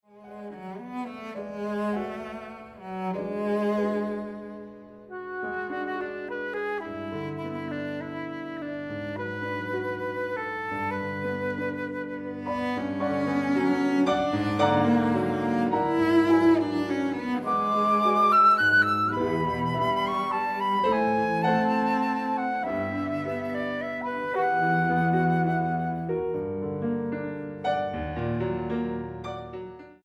para flauta, violonchelo y piano
Espressivo con anima 1.22